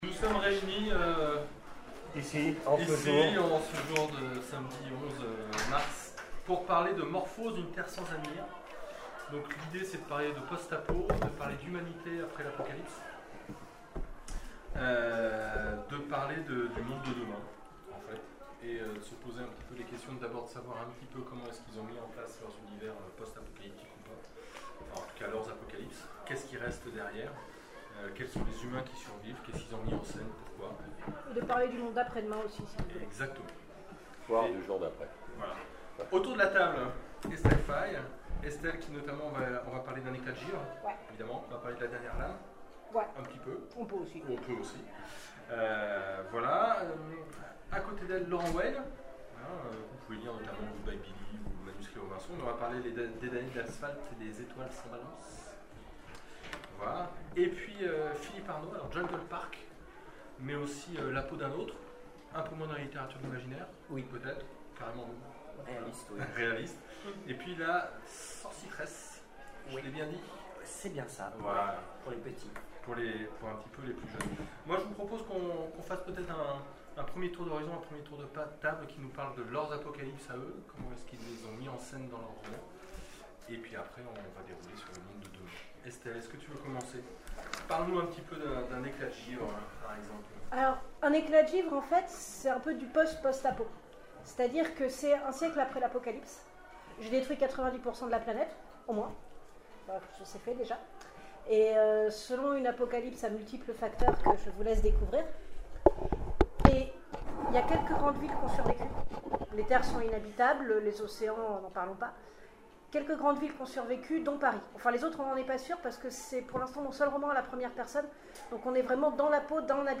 Les oniriques 2017 : conférence Une terre sans avenir ?